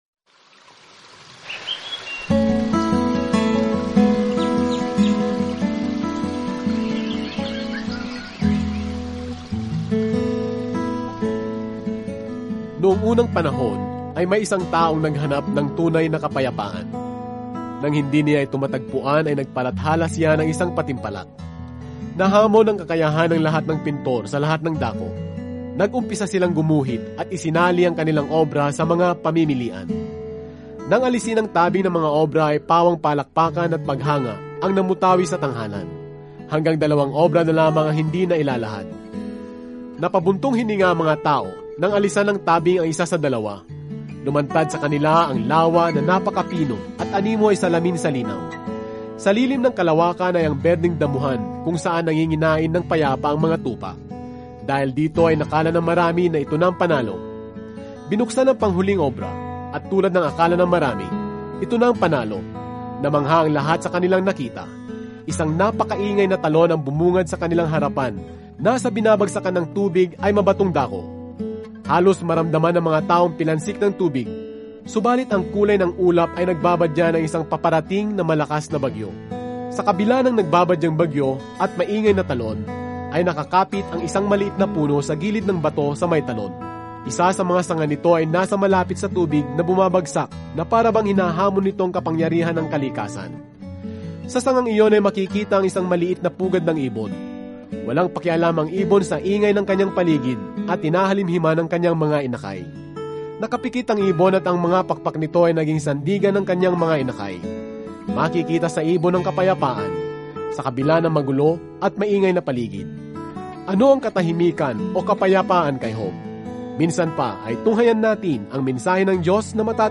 Araw-araw na paglalakbay sa Job habang nakikinig ka sa audio study at nagbabasa ng mga piling talata mula sa salita ng Diyos.